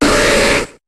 Cri de Flagadoss dans Pokémon HOME.